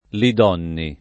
[ li d 0 nni ]